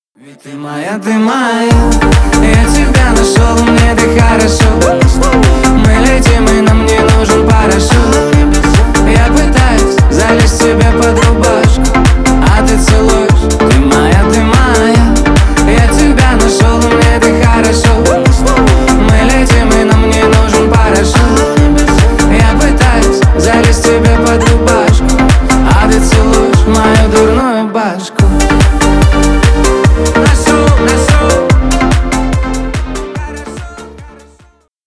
бесплатный рингтон в виде самого яркого фрагмента из песни
Поп Музыка